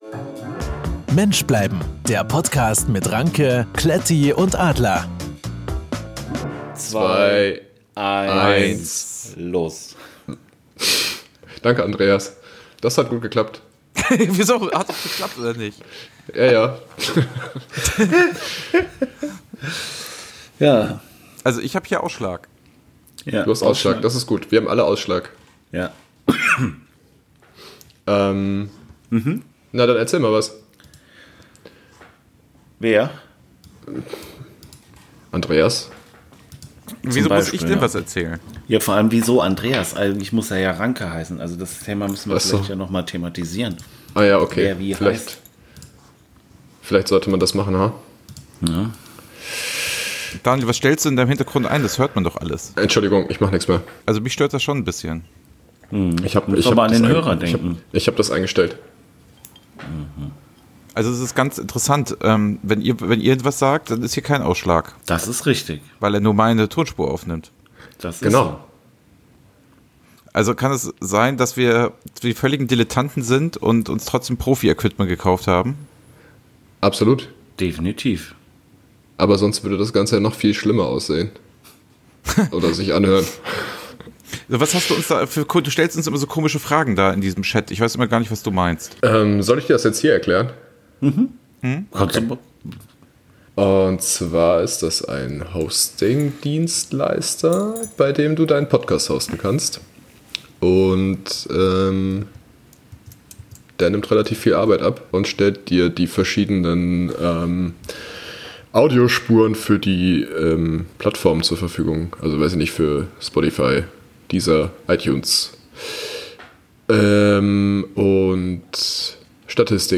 Wenn sich drei Menschen vornehmen, einen Podcast zu machen und sich nicht richtig vorbereiten, kommt so etwas dabei heraus. Mensch bleiben - Der Podcast hat seinen ersten Testlauf absolviert.